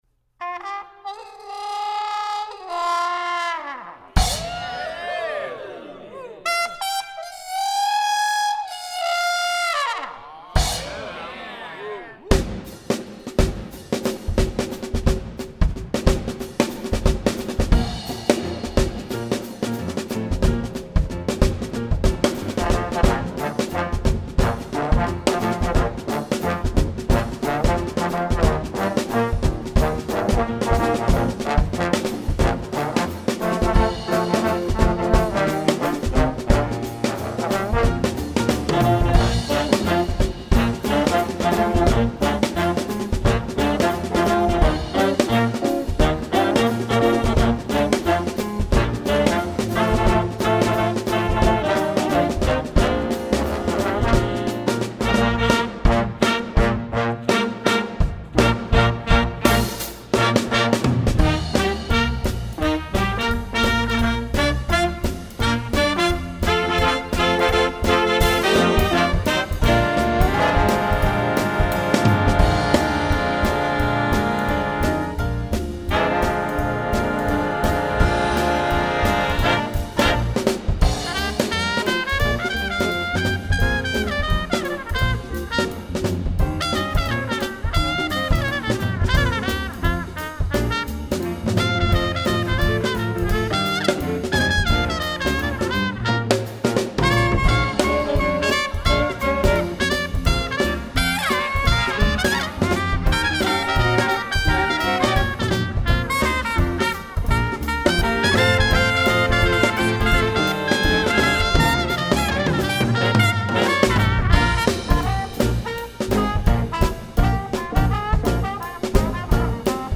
Style: New Orleans Swing
Instrumentation: Standard Big Band
Trumpets
Trombone
Saxophones